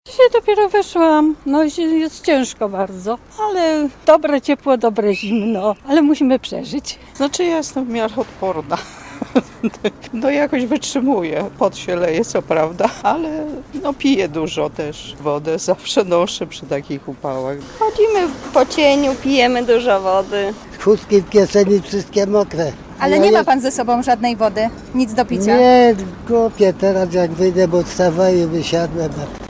Nazwa Plik Autor Łodzianie o upale audio (m4a) audio (oga) Upał może być szczególnie dokuczliwy w środkach komunikacji miejskiej.